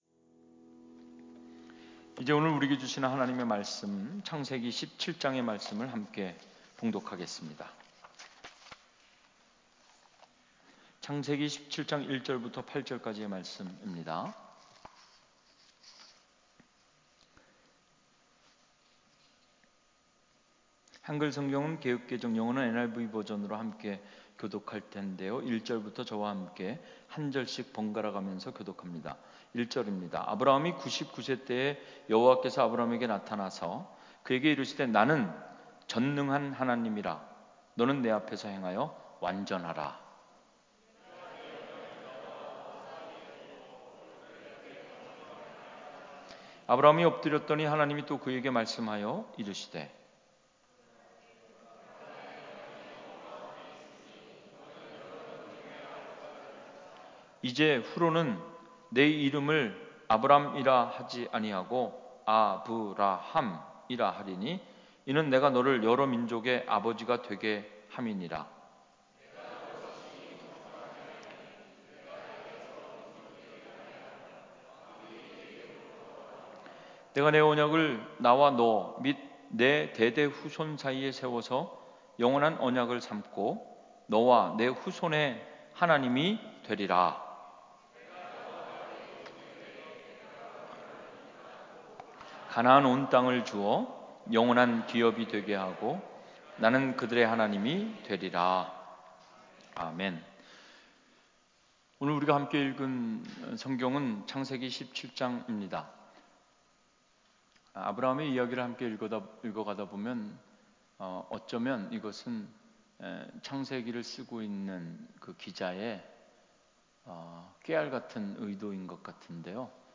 주일설교 - 2020년 03월01일 - 네 이름을 아브라함이라 하라 (Your name will be Abraham)